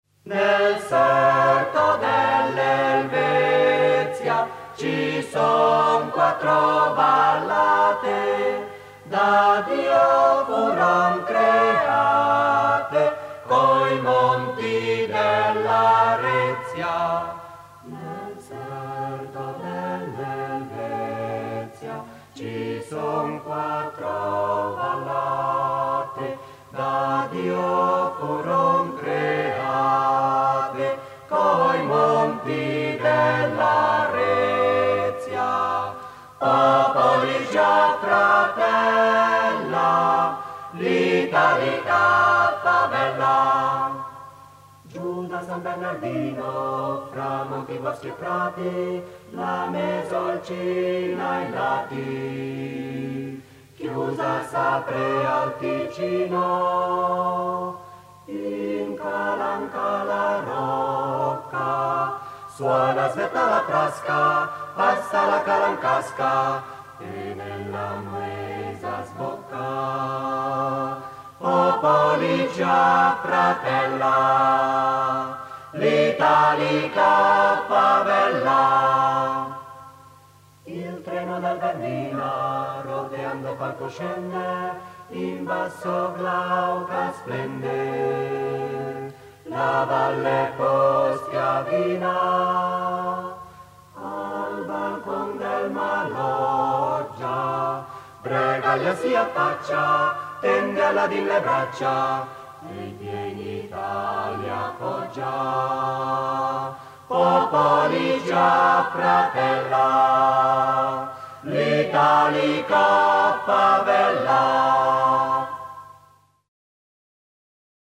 Rondo giocoso.